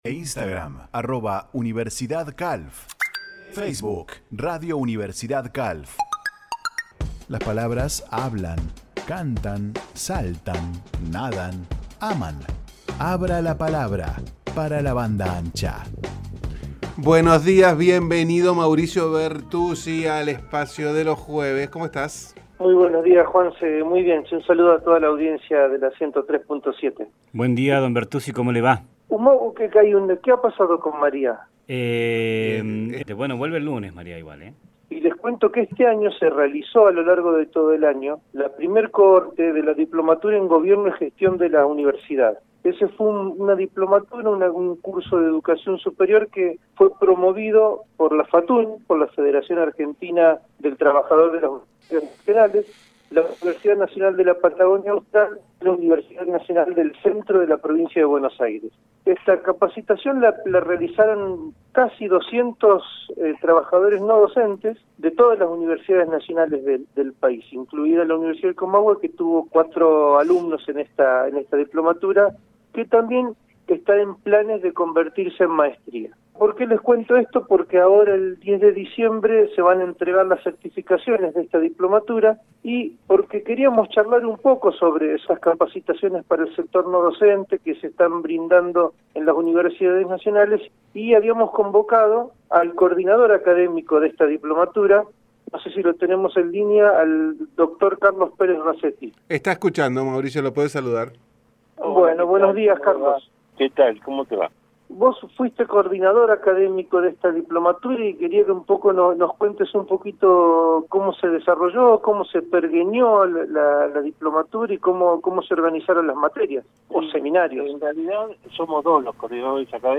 En la columna que realizamos los jueves a las 10.30 hs en el programa La Banda Ancha que se emite por FM 103.7 Universidad-Calf